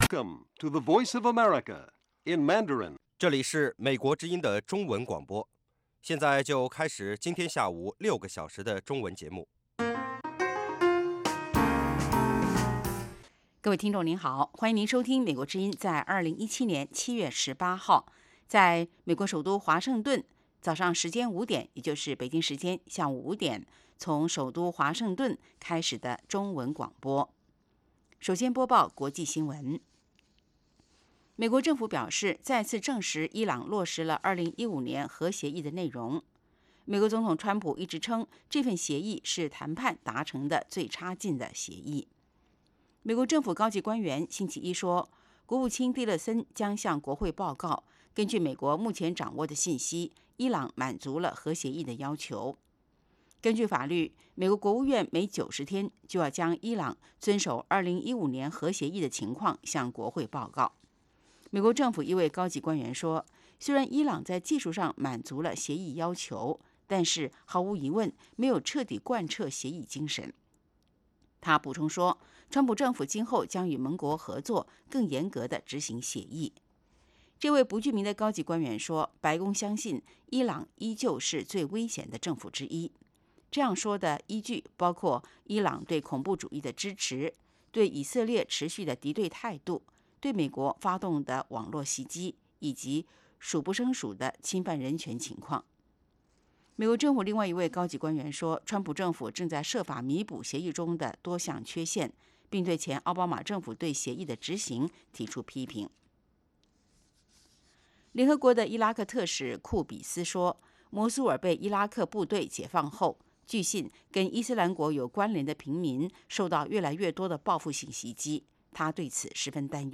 北京时间下午5-6点广播节目。